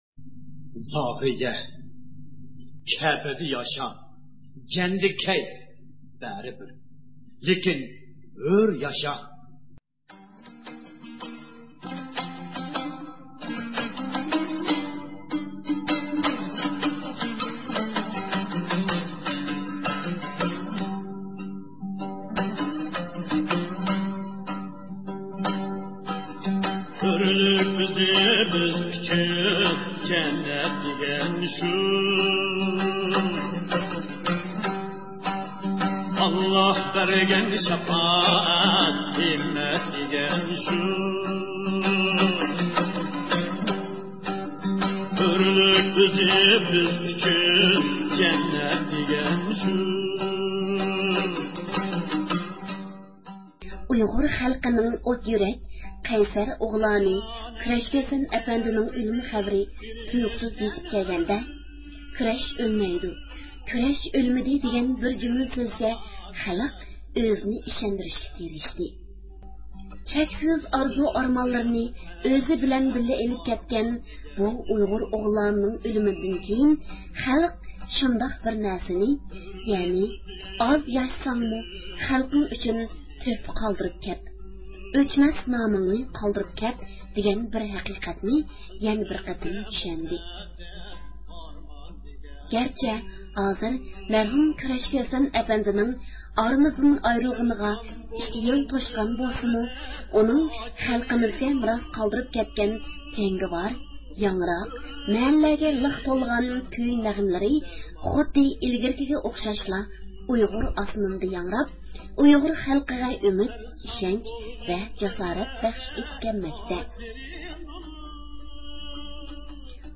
مەرھۇم كۈرەش كۈسەن ئەپەندىنىڭ ۋاپاتىنىڭ ئىككى يىللىقى توشقان بۈگۈنكىدەك قايغۇلۇق كۈندە بارلىق تۇيغۇلىرىمىزغا ئورتاقلىشىش ئۈچۈن دۇنيا ئۇيغۇر قۇرۇلتىيىنىڭ رەئىسى،ئۇيغۇرلارنىڭ مىللىي رەھبىرى رابىيە قادىر خانىمنى زىيارەت قىلغىنىمىزدا، ئۇ ئۆزىنىڭ كۈرەش كۈسەن ئەپەندى ھەققىدىكى قايغۇسى ۋە يۈرەك سۆزلىرىنى ئىپادىلىدى. كۈرەش كۈسەن ئەپەندىنىڭ يارقىن ئىجادىيەتلىرىدىن بىرى بولغان رابىيە قادىر ناملىق ناخشا توغرىسىدا رابىيە قادىر خانىم ئۆز تەسىراتلىرى ھەققىدە توختالدى.